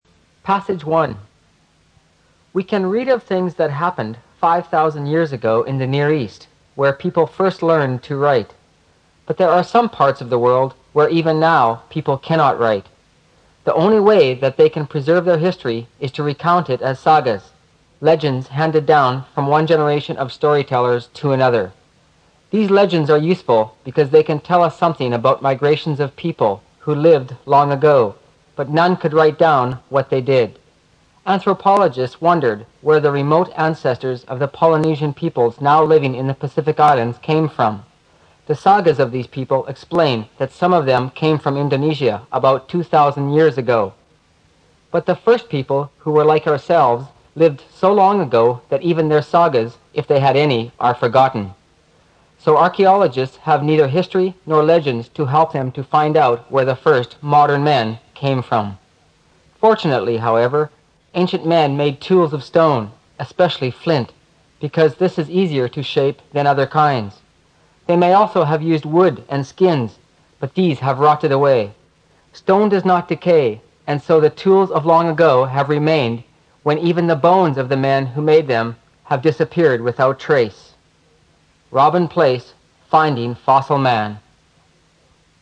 新概念英语85年上外美音版第四册 第1课 听力文件下载—在线英语听力室